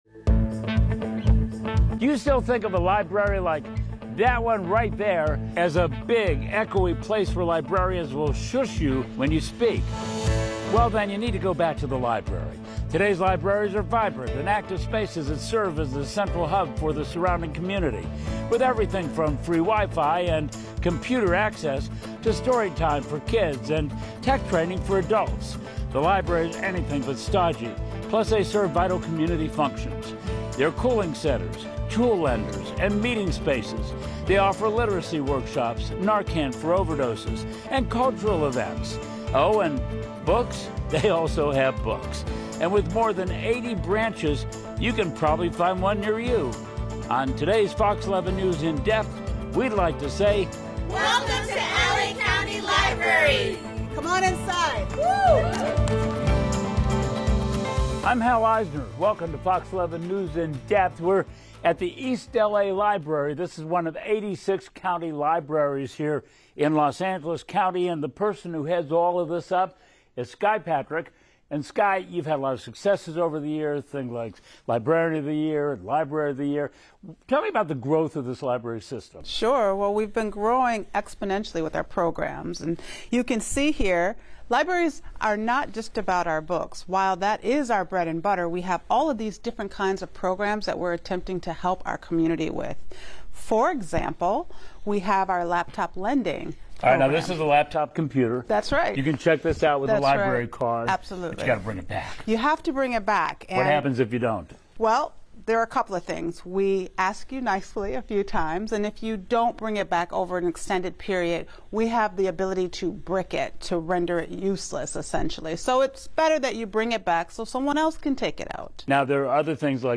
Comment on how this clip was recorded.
at the East LA Library